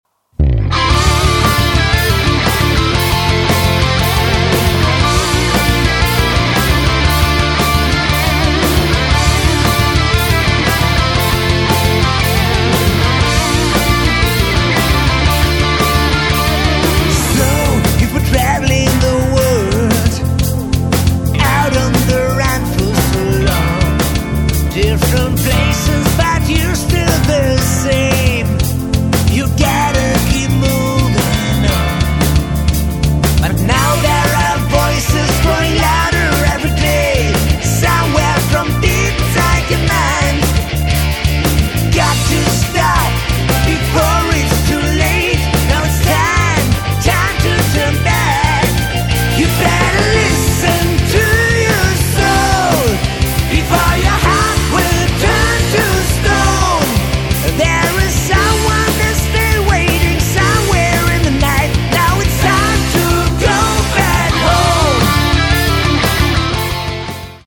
Vocals / Guitar
Bass
Keyboards
Drums